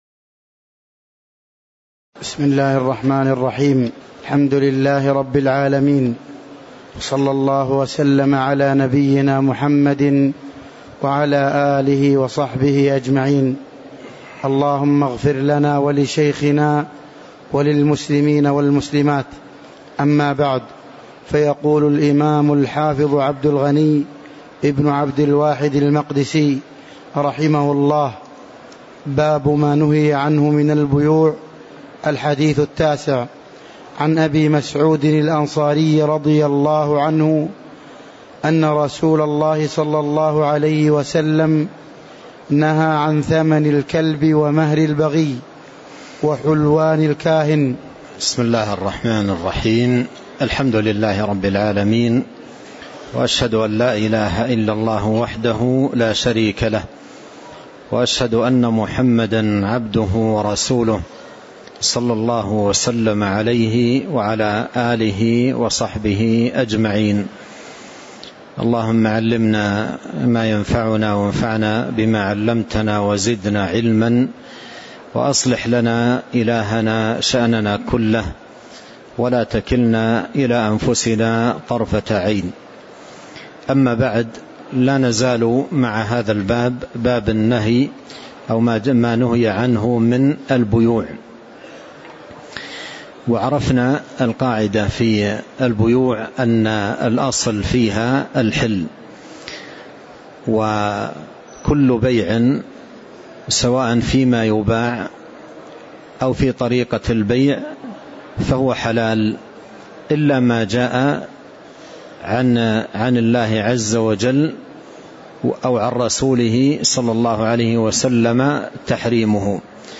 تاريخ النشر ٢ رجب ١٤٤٤ هـ المكان: المسجد النبوي الشيخ